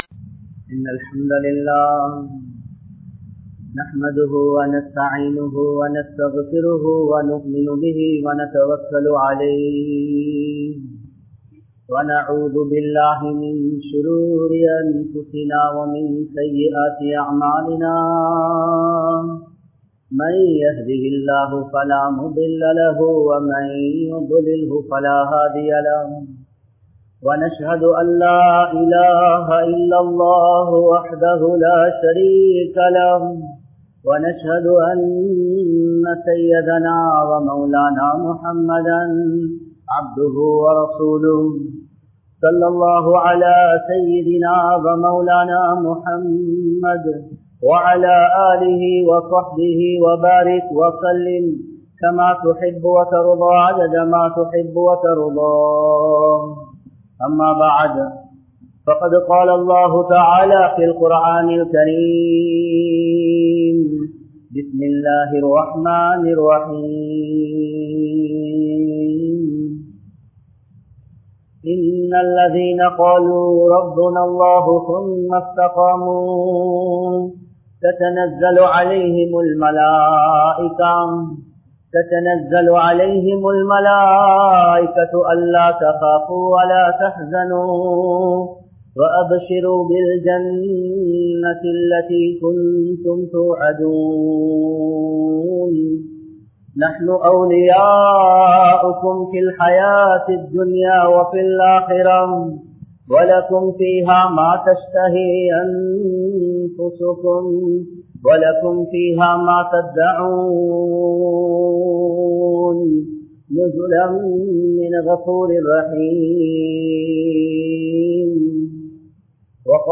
Indraya Muslimkalin Nilamai | Audio Bayans | All Ceylon Muslim Youth Community | Addalaichenai